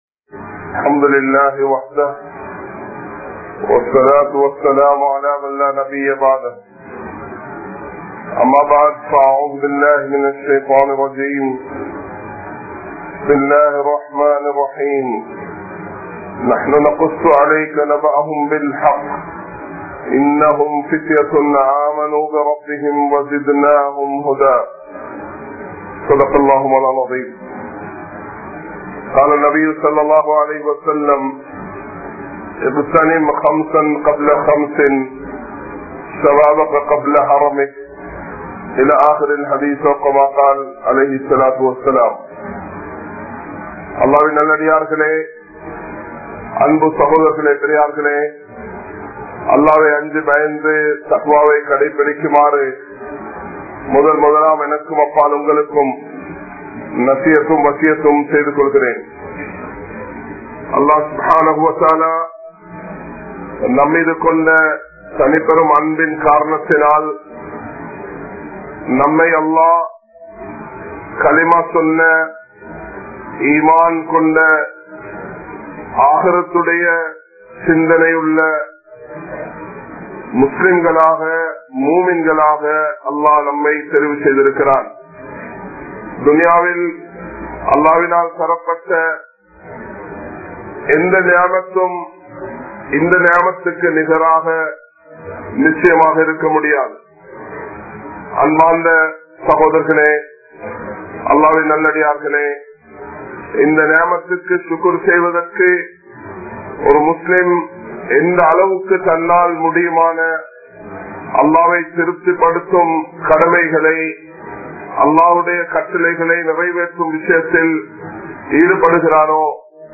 Vaalifarhalum Kaathalarthinamum (வாலிபர்களும் காதலர் தினமும்) | Audio Bayans | All Ceylon Muslim Youth Community | Addalaichenai
Kollupitty Jumua Masjith